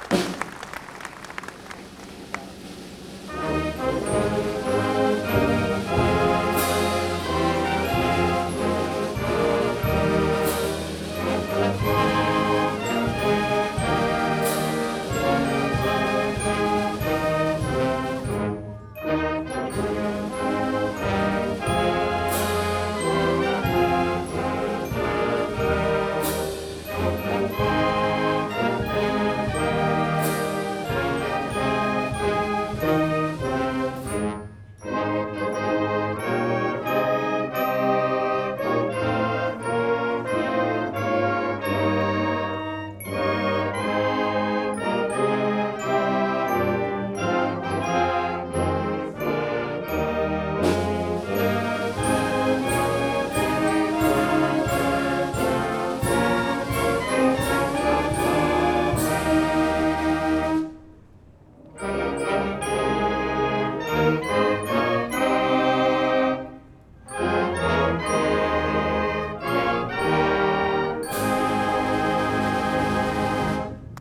LISTEN TO THE BAND!